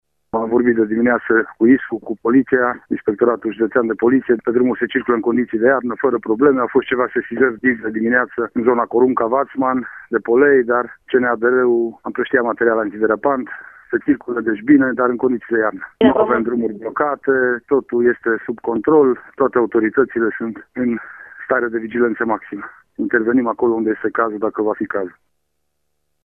Dimineaţă au fost unele probleme cauzate de polei în zona Vatman din Corunca, dar s-a intervenit cu material antiderapant, a mai spus prefectul:
prefect-drumuri.mp3